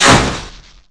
Switched Impaler fire sound to something more appropriate (proto Skaarj fire).